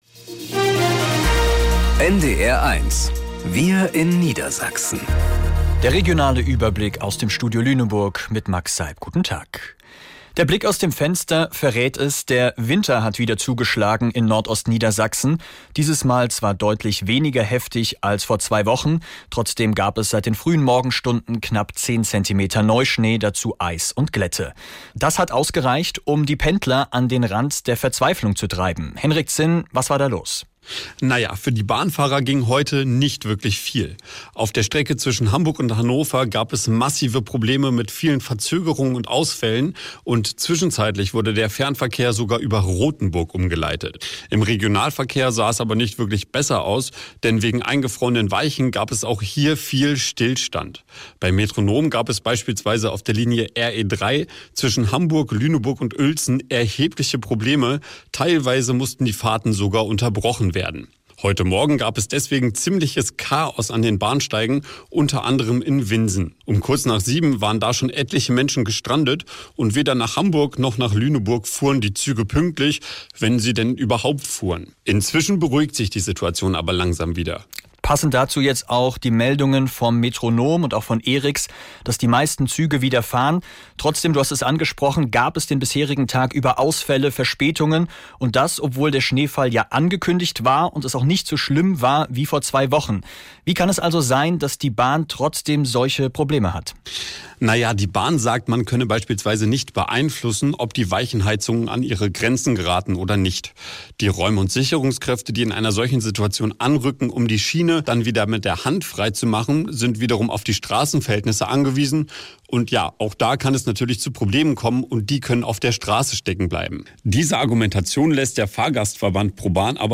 Wir in Niedersachsen - aus dem Studio Lüneburg | Nachrichten Podcast
Genres: Daily News, News